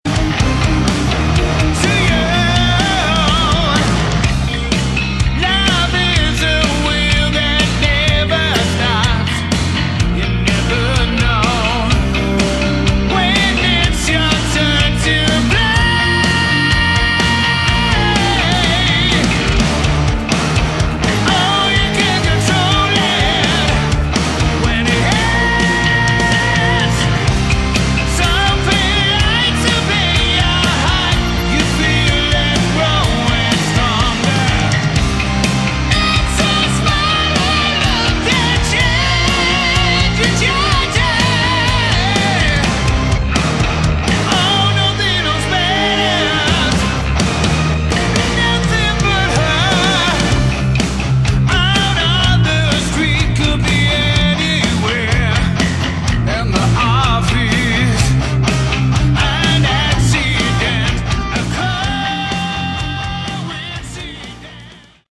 Category: Melodic Metal
vocals
guitar
bass
drums